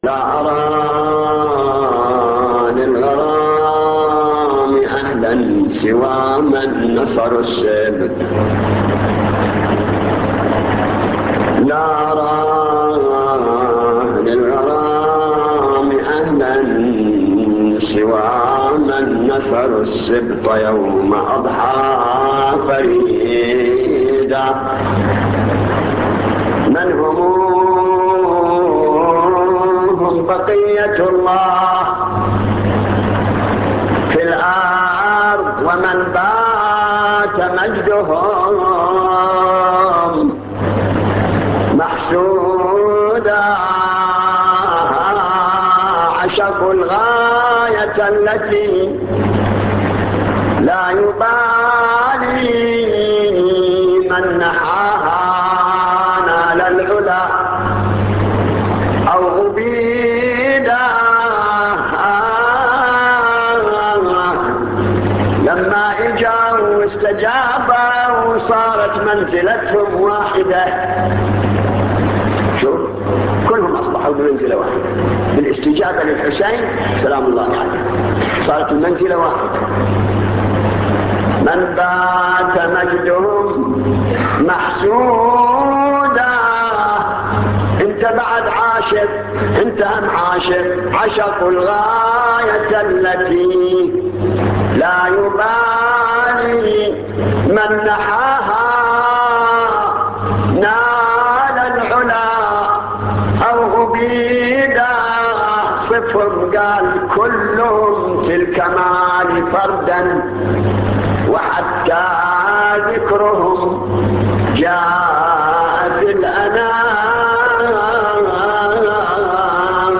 نواعي حسينية 12